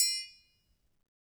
Triangle6-HitM_v1_rr1_Sum.wav